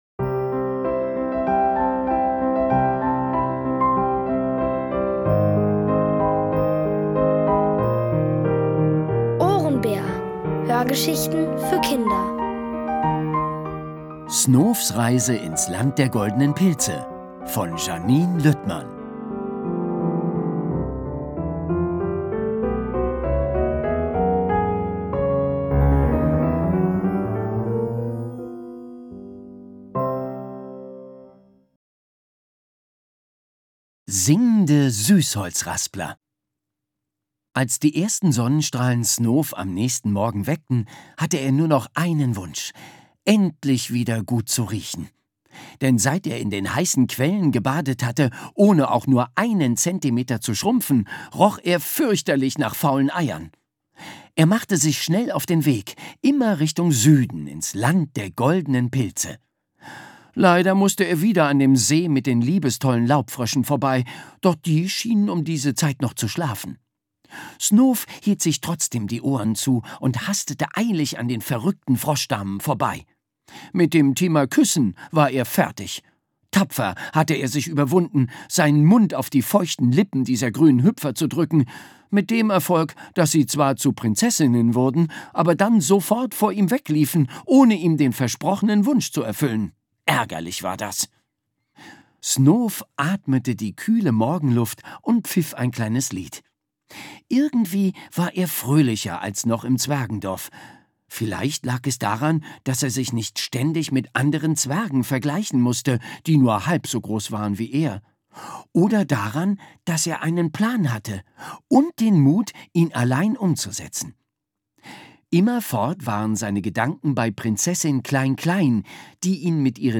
Von Autoren extra für die Reihe geschrieben und von bekannten Schauspielern gelesen.